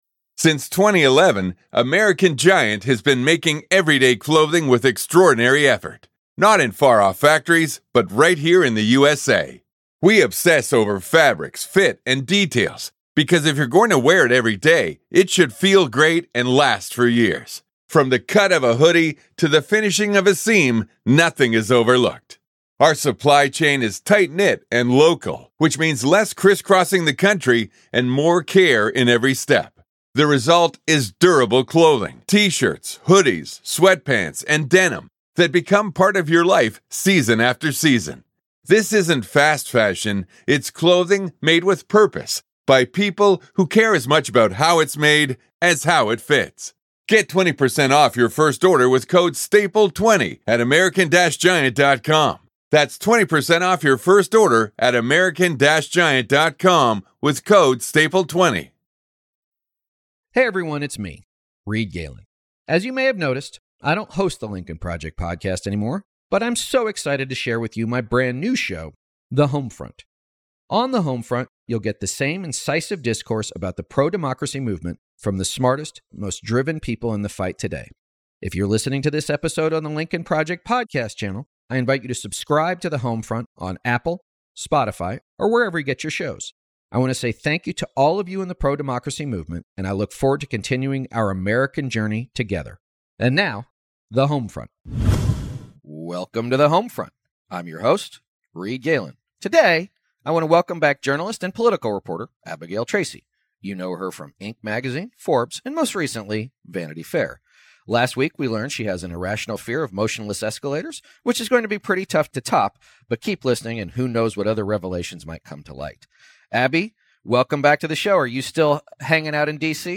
journalist and political reporter